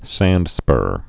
(săndspûr)